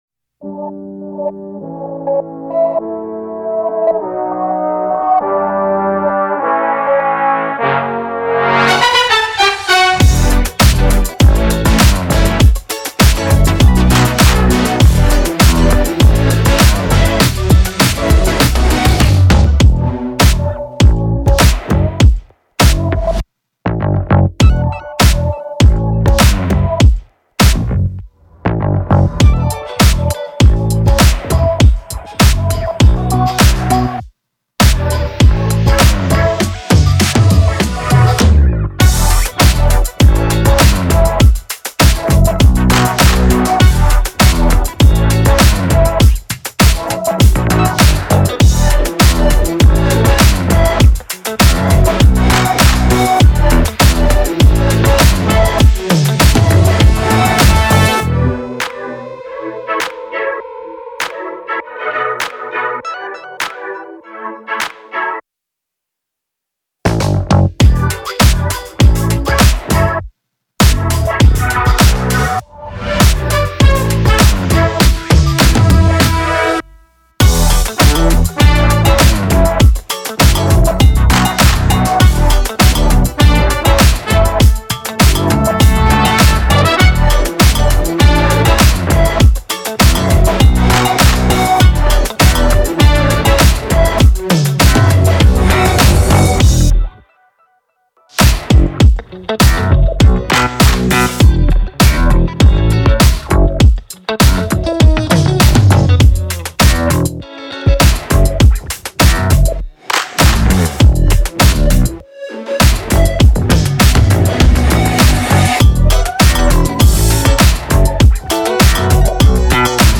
Pop Instrumentals